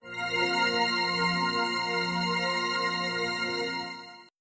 Windows X13 Shutdown.wav